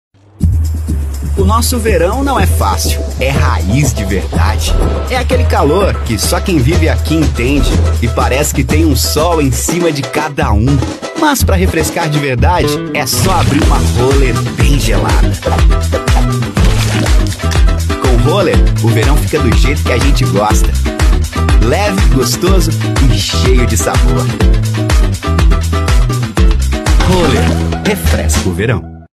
DEMOSTRATIVO ROLLER :
Padrão
Animada